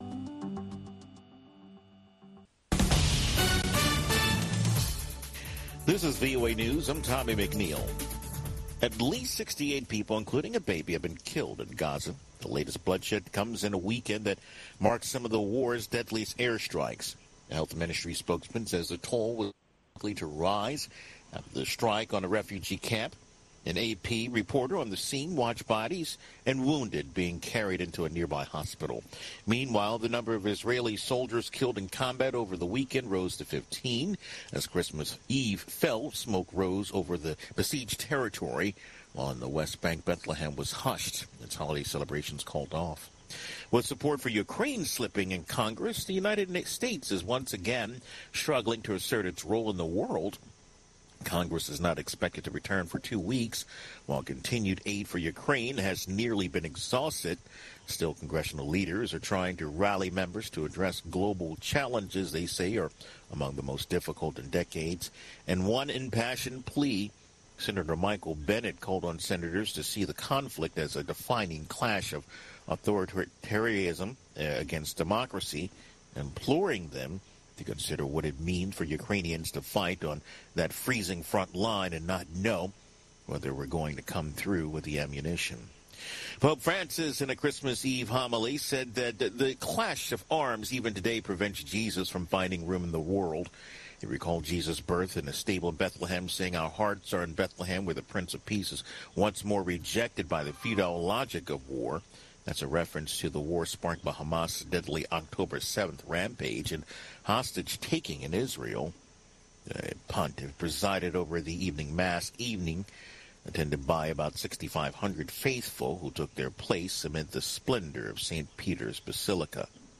Two-Minute Newscast